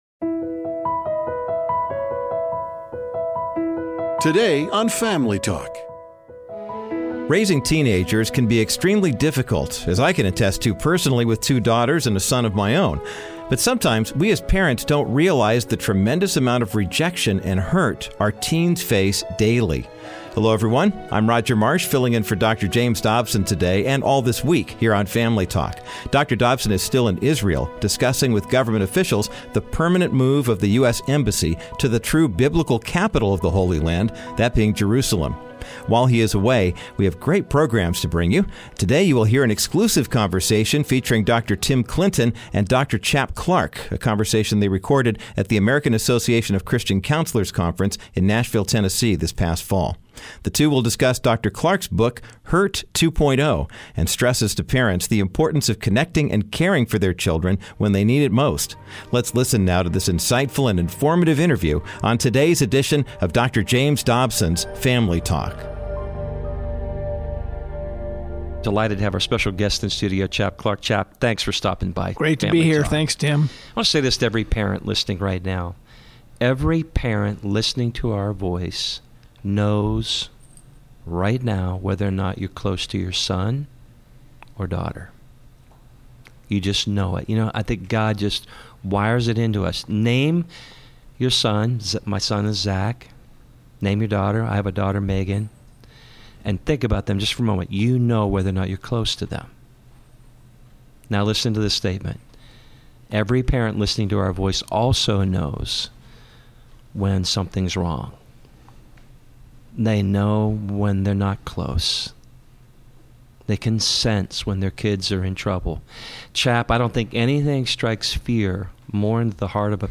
Dont miss this exclusive conversation today on Dr. James Dobsons Family Talk.